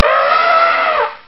دانلود صدای فیل 6 از ساعد نیوز با لینک مستقیم و کیفیت بالا
جلوه های صوتی
برچسب: دانلود آهنگ های افکت صوتی انسان و موجودات زنده دانلود آلبوم انواع صدای فیل از افکت صوتی انسان و موجودات زنده